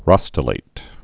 (rŏstə-lāt, rŏ-stĕlĭt)